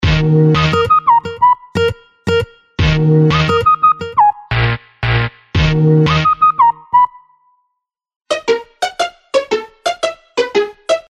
哨子和刺针环路
描述：口哨、低音刺和pizzi合成器都略带混响，在fl 8xxl中完成 随心所欲地使用。